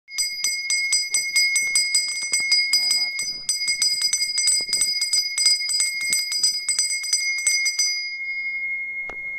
Ice cream Bell sound
Ice_cream_Bell_sound.mp3